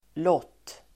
Uttal: [låt:]